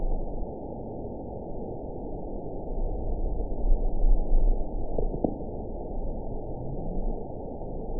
event 922700 date 03/12/25 time 21:29:46 GMT (1 month, 2 weeks ago) score 8.90 location TSS-AB10 detected by nrw target species NRW annotations +NRW Spectrogram: Frequency (kHz) vs. Time (s) audio not available .wav